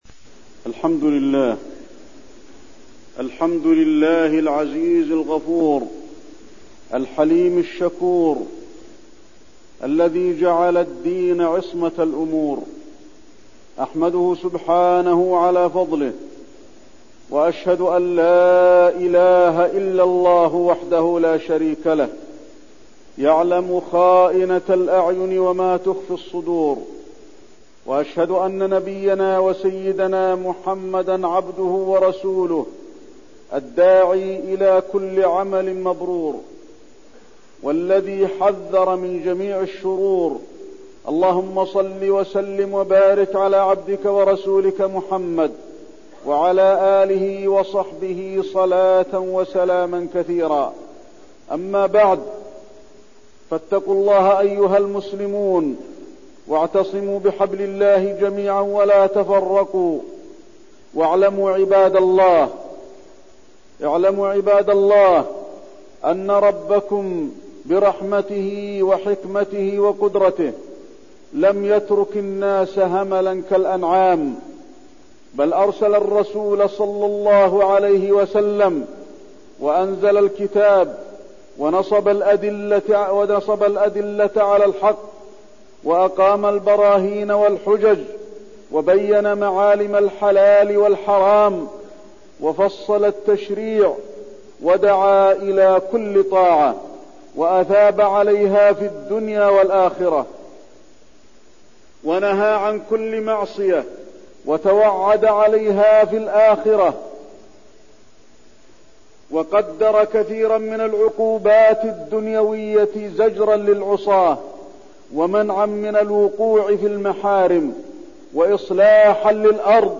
تاريخ النشر ٢٩ ربيع الثاني ١٤١١ هـ المكان: المسجد النبوي الشيخ: فضيلة الشيخ د. علي بن عبدالرحمن الحذيفي فضيلة الشيخ د. علي بن عبدالرحمن الحذيفي التمسك بالإسلام